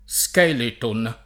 vai all'elenco alfabetico delle voci ingrandisci il carattere 100% rimpicciolisci il carattere stampa invia tramite posta elettronica codividi su Facebook skeleton [ S k $ leton ; ingl. S k $ litën ] s. m. (sport.)